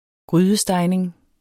Udtale [ ˈgʁyːðəˌsdɑjneŋ ]